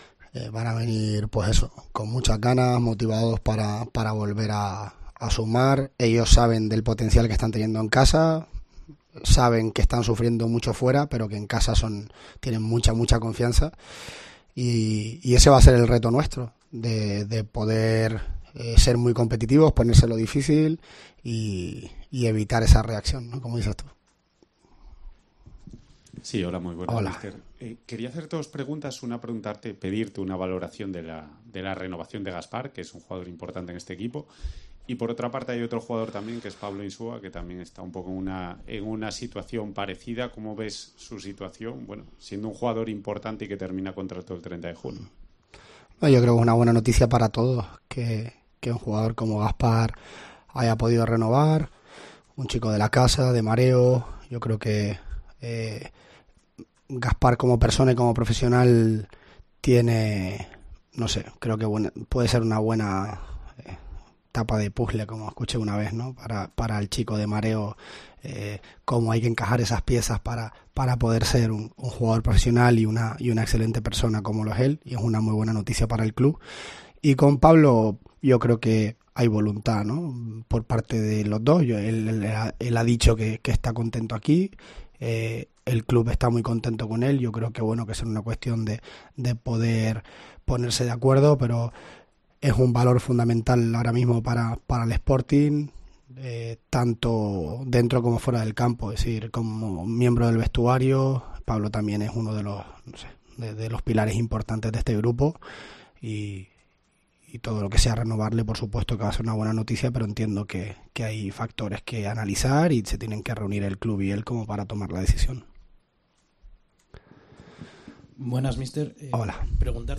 Entrenamiento y rueda de prensa de Miguel Ángel Ramírez